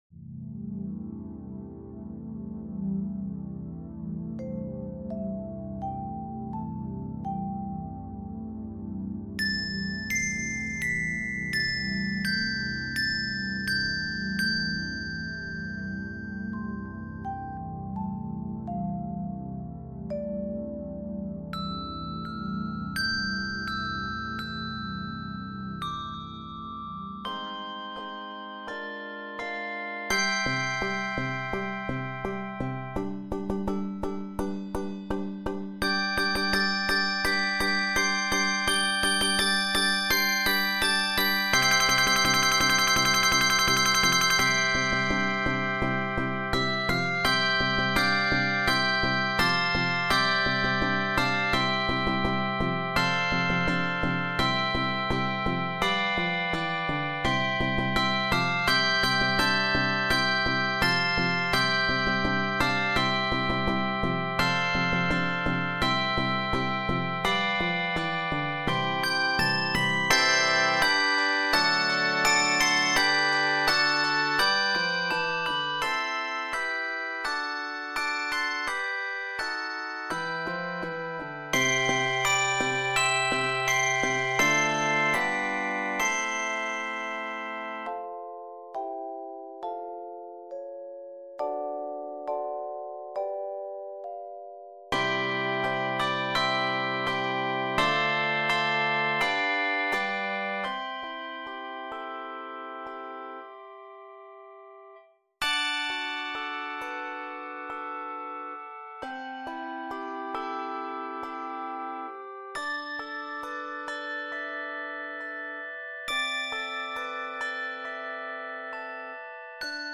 exciting and uplifting medley
handbell anthem